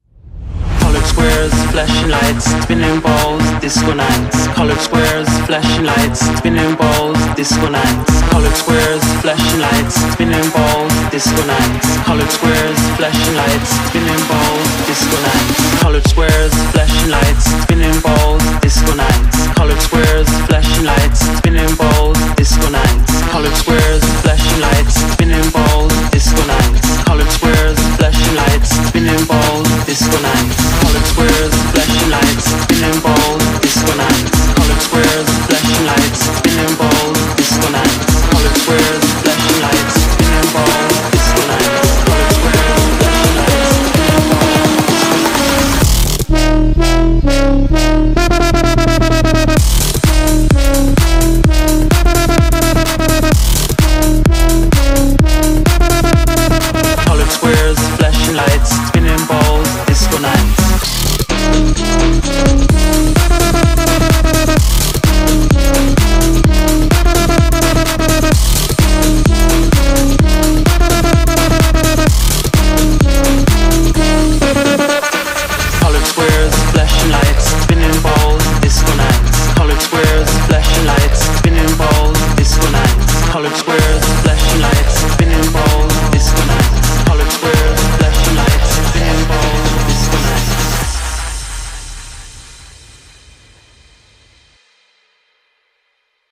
BPM128
Audio QualityMusic Cut